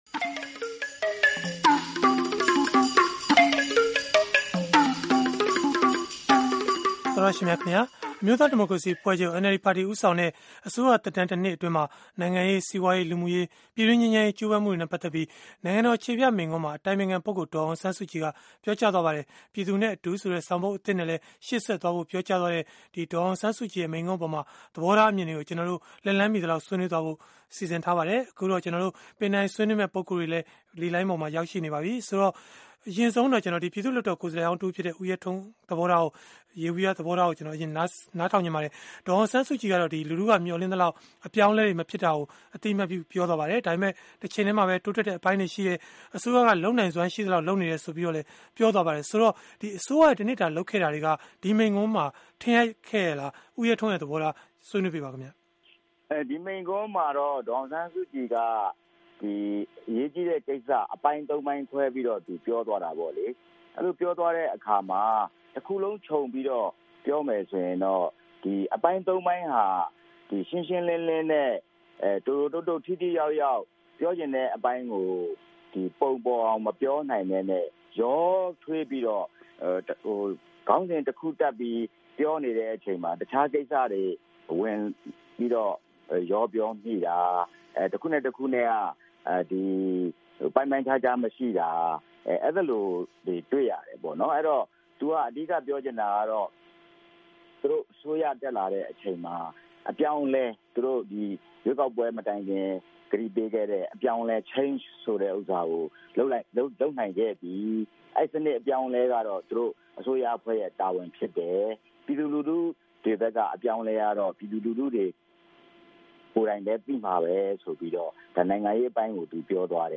အစိုးရတစ်နှစ်ပြည့် ဒေါ်စုမိန့်ခွန်းအပေါ် တိုက်ရိုက်ဆွေးနွေးခန်း